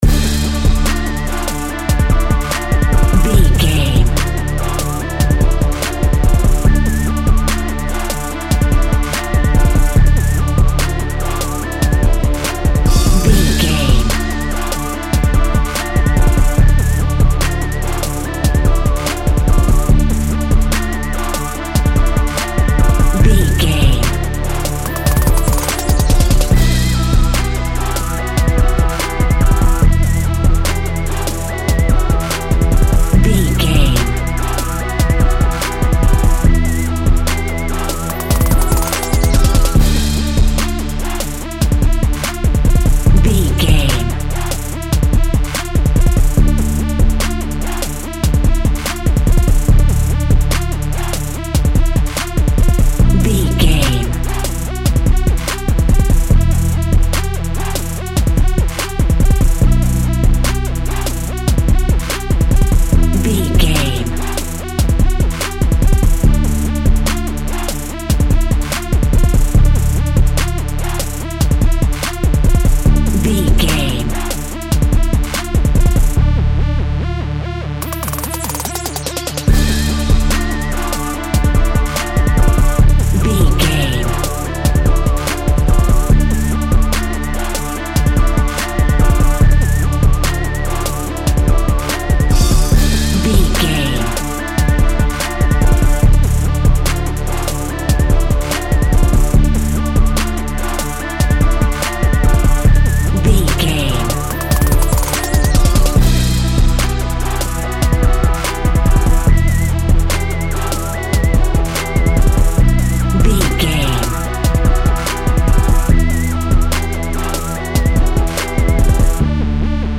Aeolian/Minor
D
ominous
dark
eerie
synthesizer
drum machine
instrumentals
horror music